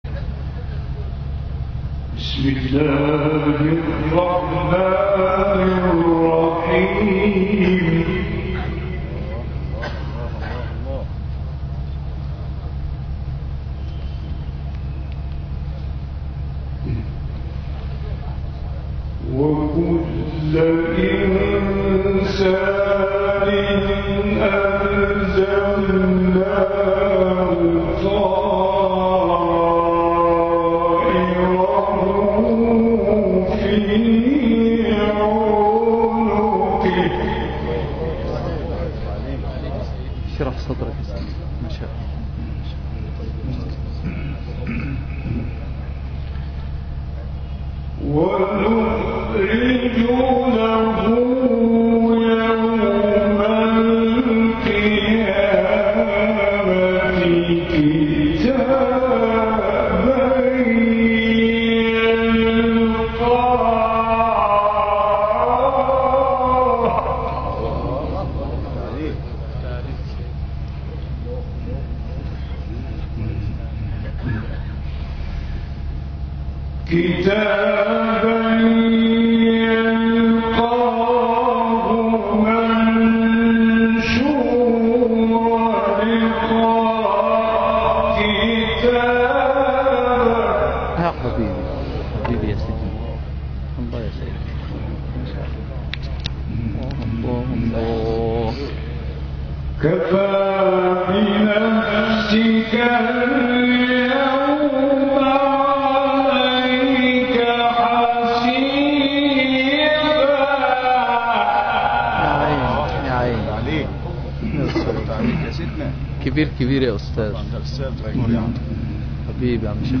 تلاوت کوتاهی از «غلوش» در سال 2013 میلادی
گروه شبکه اجتماعی: تلاوت راغب مصطفی غلوش در سال 2013 میلادی در شهر طنطا مصر را می‌شنوید.
در این تلاوت کوتاه راغب مصطفی غلوش به تلاوت آیات 13، 14 و 15 سوره اسراء می‌پردازد که این تلاوت در روستای محل تولدش، روستای برما در شهر طنطا مصر اجرا شده است.